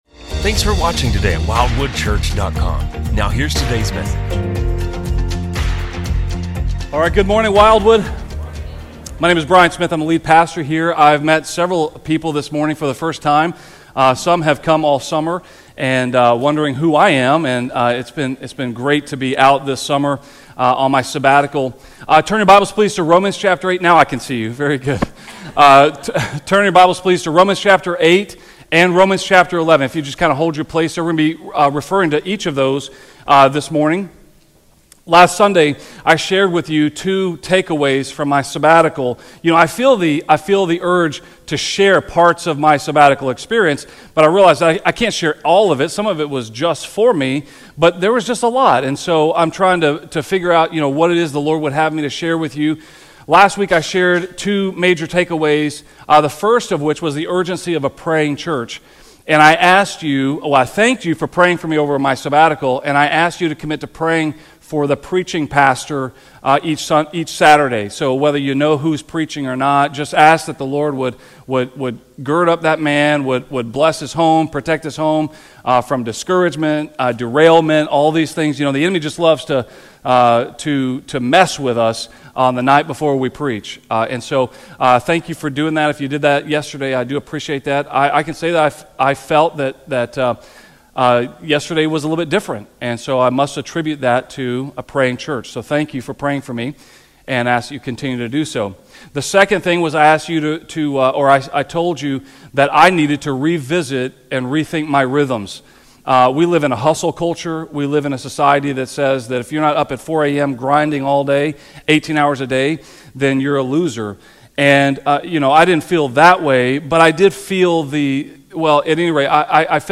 A message from the series "Wisdom From Above."